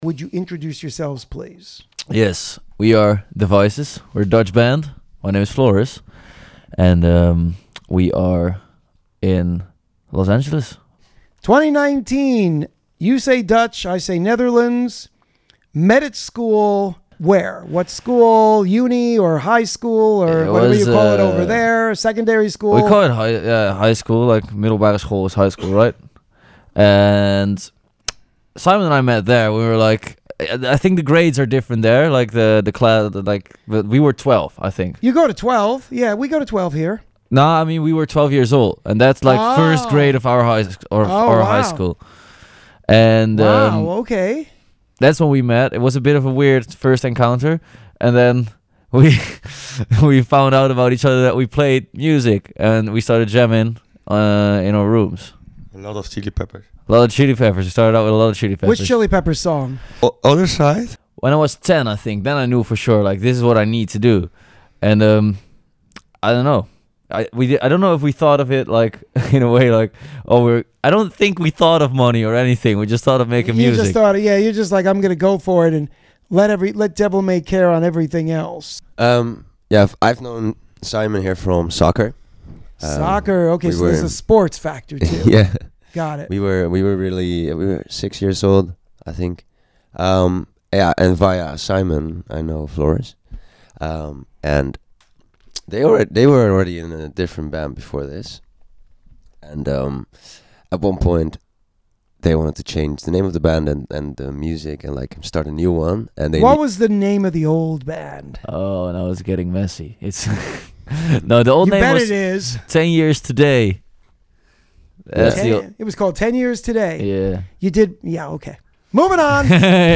This Week's Interview (03/23/2025): The Vices